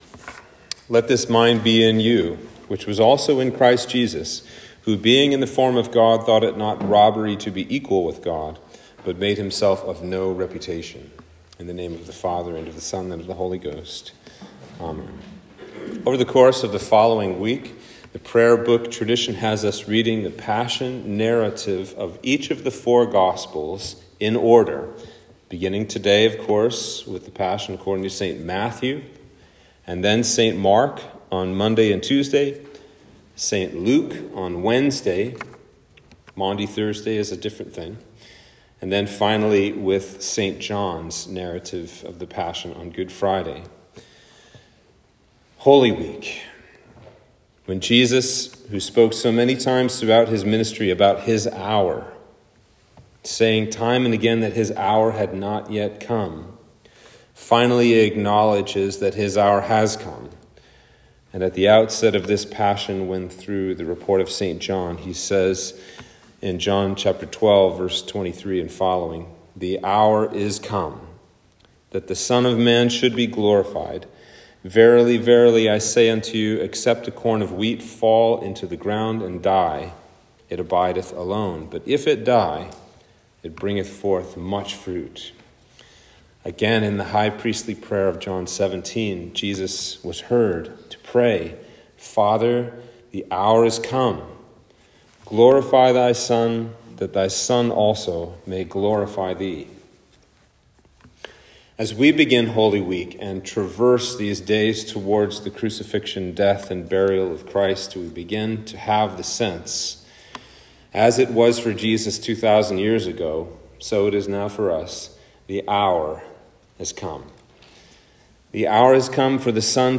Sermon for Palm Sunday